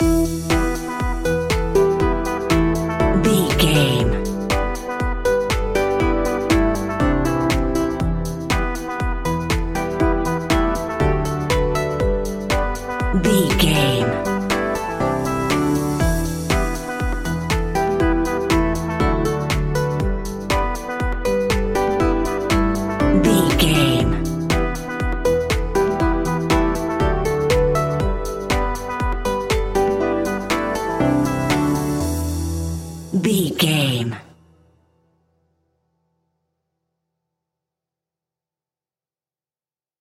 Aeolian/Minor
cheerful/happy
hopeful
synthesiser
drums
drum machine
electric piano
acoustic guitar
electronic
techno
trance
synth leads
synth bass